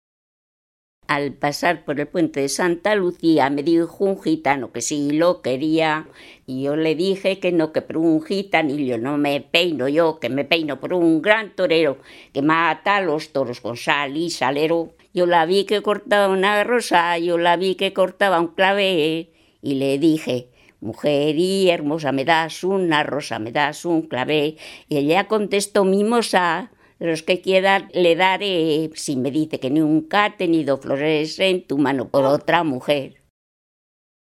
Clasificación: Cancionero
Lugar y fecha de recogida: Logroño, 11 de septiembre de 2007
Canción de mocedad femenina con letra de primeros galanteos.